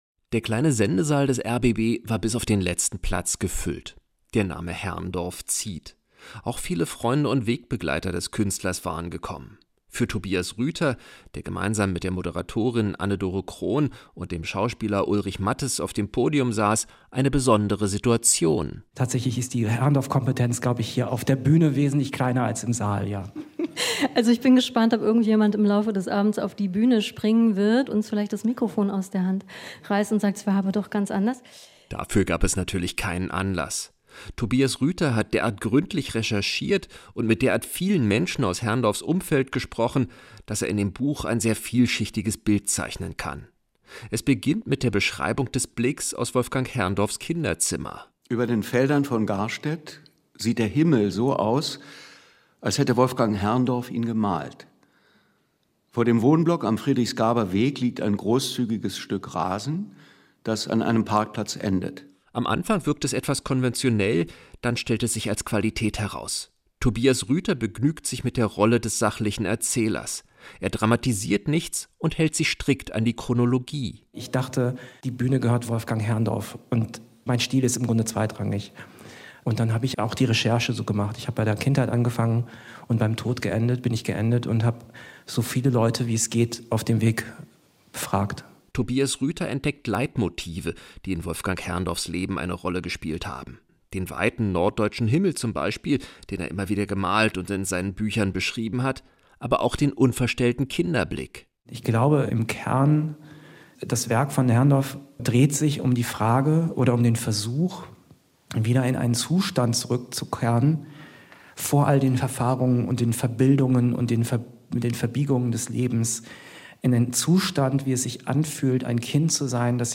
Inforadio Nachrichten, 22.09.2023, 13:20 Uhr - 22.09.2023